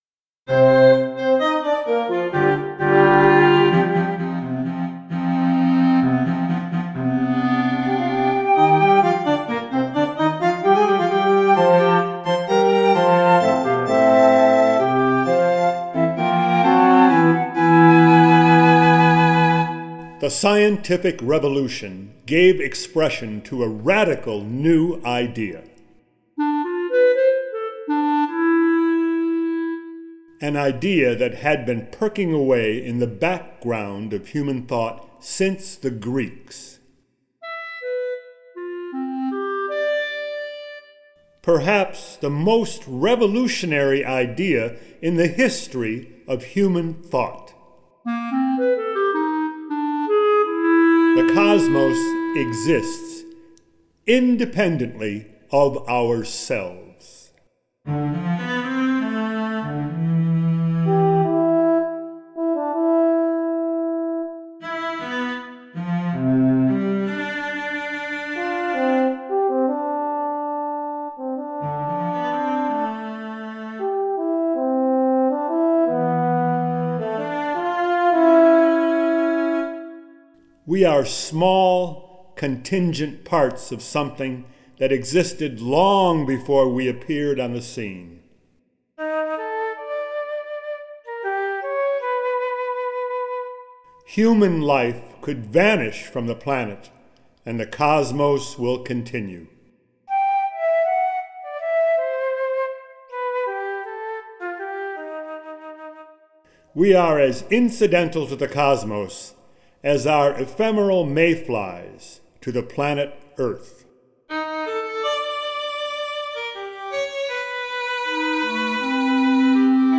Antiphon for speaker and instrumental ensemble
flute, clarinet, bassoon,  French horn, violin, cello